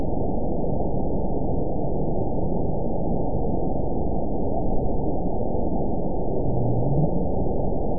event 921966 date 12/23/24 time 19:22:35 GMT (5 months, 3 weeks ago) score 9.17 location TSS-AB04 detected by nrw target species NRW annotations +NRW Spectrogram: Frequency (kHz) vs. Time (s) audio not available .wav